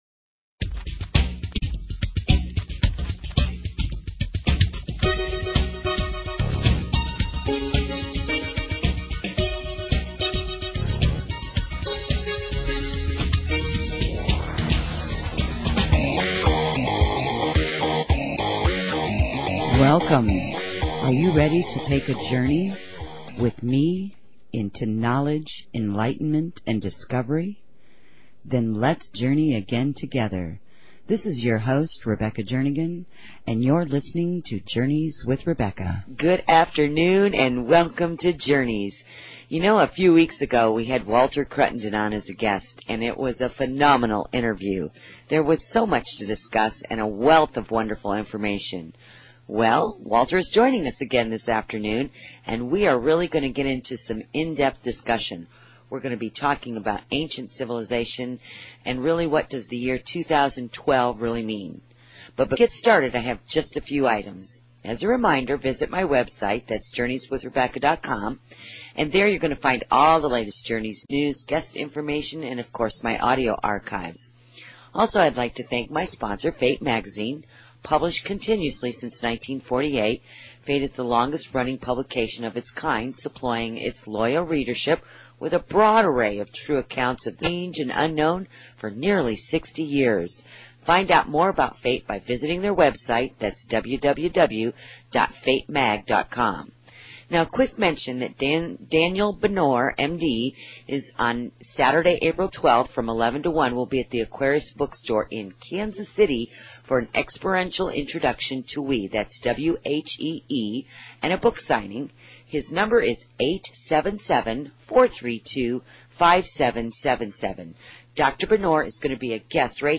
Talk Show Episode
Interview w